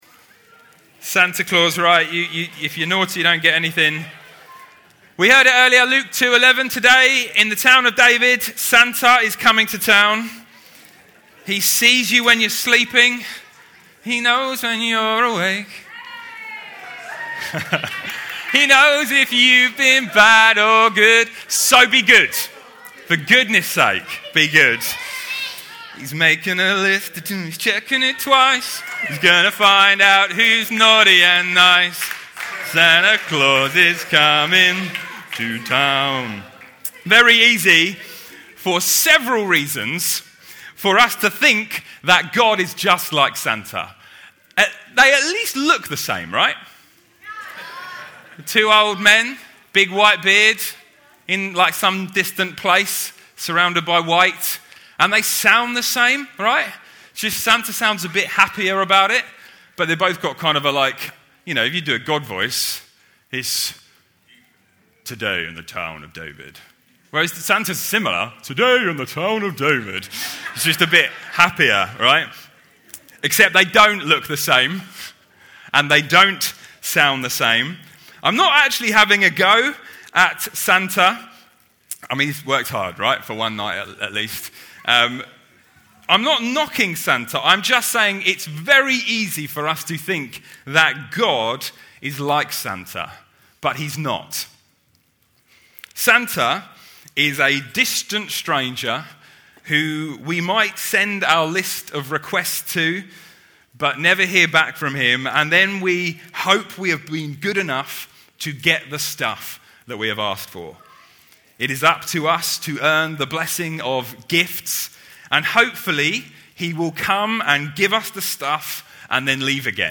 Series: Other Sermons 2024